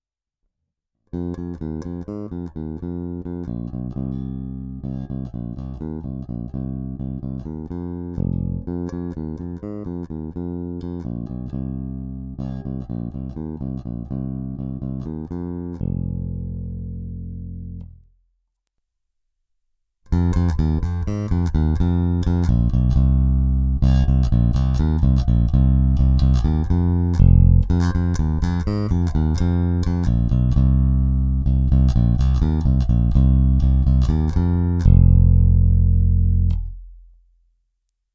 Zvuk je trochu nezařaditelný. Dobrý, pevný, ale charakter Music Manu v něm není ani v náznaku, což jsem upřímně řečeno vlastně vůbec neočekával. Originál Music Man snímač a aktivní elektronika jsou prostě téměř nenahraditelné. Tato aktivní elektronika poskytuje hodně čistý, ničím nezabarvený zvuk, a nutno dodat, že má hodně silný výstup, velký zdvih.
Bonusová nahrávka 2, kdy v první části je aktivní elektronika na minimu, v druhé na maximu, aby se ukázal rozsah signálu.